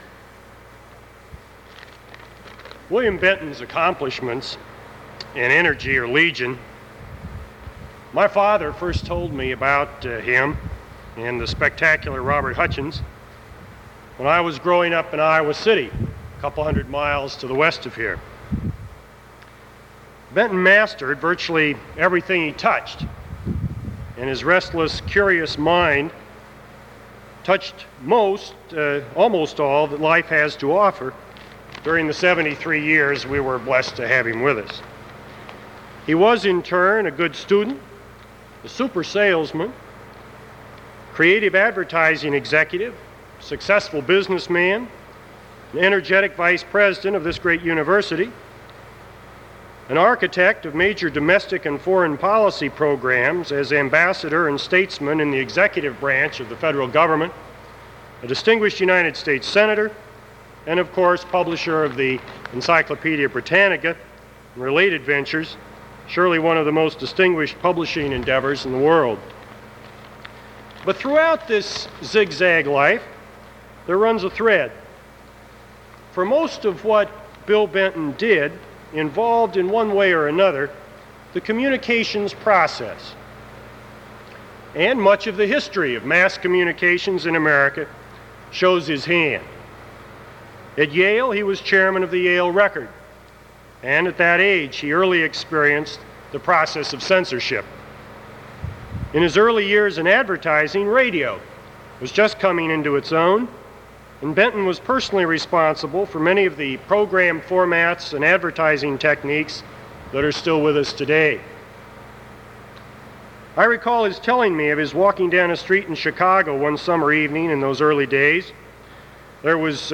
Nicholas Johnson was asked to speak at a memorial service for William Benton in Chicago; these are his remarks on that occasion (7:28).